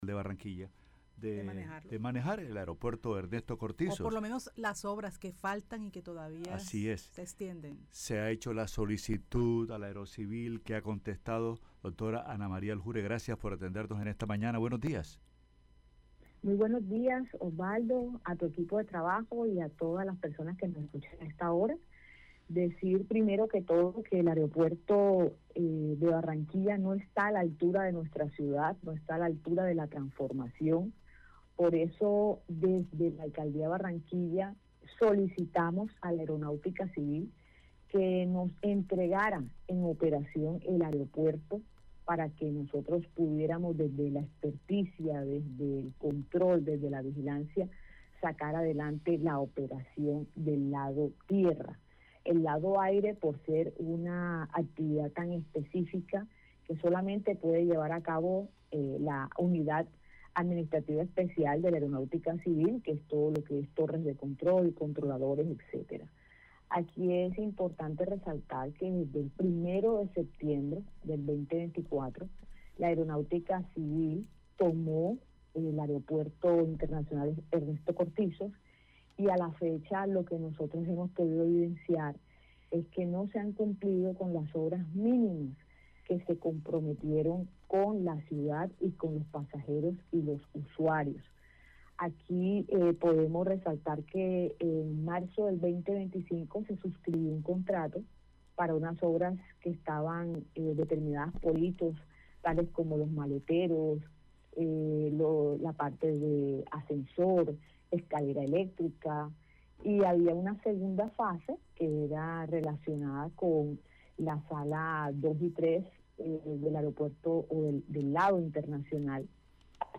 Ana-Maria-Aljure-Gerente-de-Ciudad-.mp3